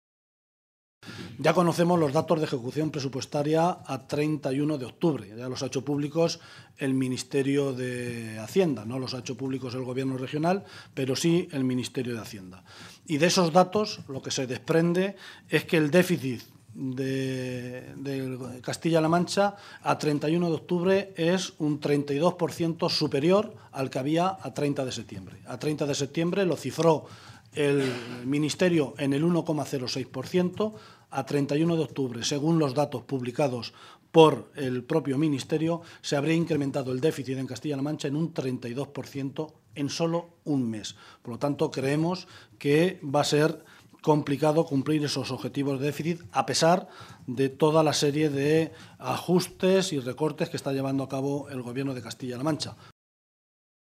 Guijarro se pronunciaba de esta manera en una comparecencia ante los medios de comunicación en la que insistía en que a pesar de todos los brutales recortes, a pesar de haber paralizado la economía y haber convertido a Castilla-La Mancha “en la región en la que más ha subido el paro de España durante el año 2012”, a pesar de tener 55.000 parados más que cuando Cospedal llegó al Gobierno, a pesar de haber despedido a miles de empleados públicos y de haber desmantelado la Sanidad y la Educación, “el Ministerio de Hacienda le pide a Cospedal que diga antes del 15 de Enero de este año qué medidas adicionales va a adoptar porque no cumple su Plan de Equilibrio y no va a poder cumplir el déficit”.
Cortes de audio de la rueda de prensa